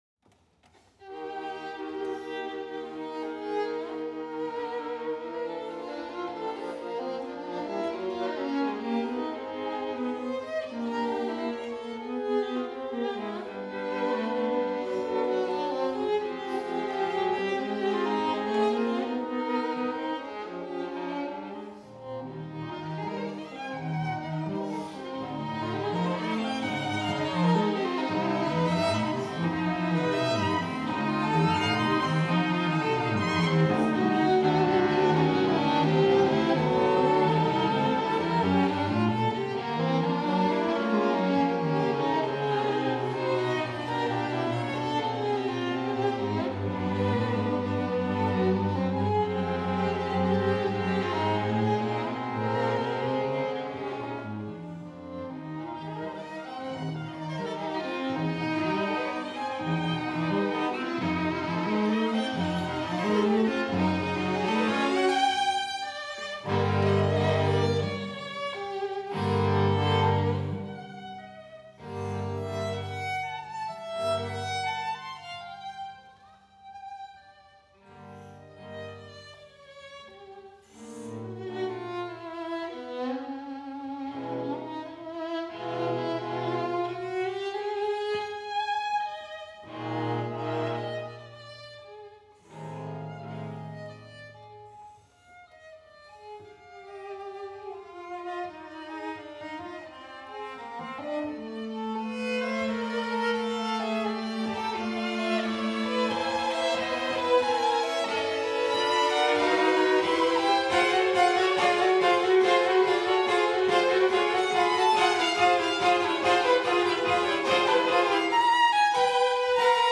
Venue: St. Brendan’s Church